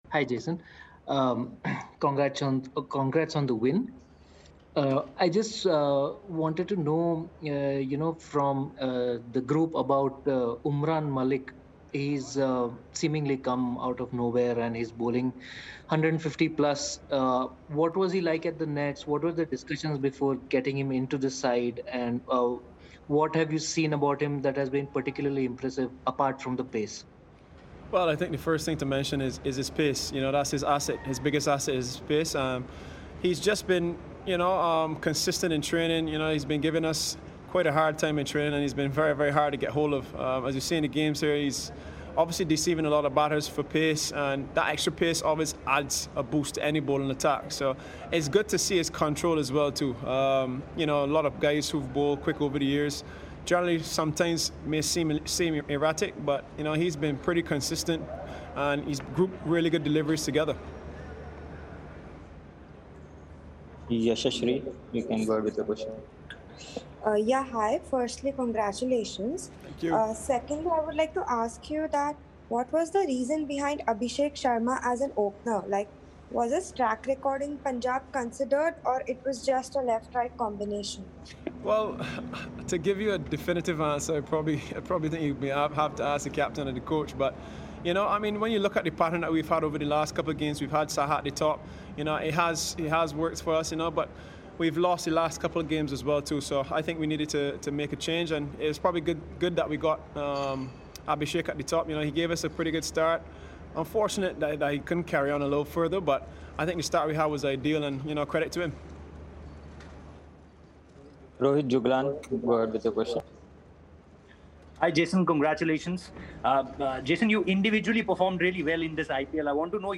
Jason Holder of Sunrisers Hyderabad addressed the media after the game
Jason Holder of Sunrisers Hyderabad and Mike Hesson of Royal Challengers Bangalore addressed the media after the game.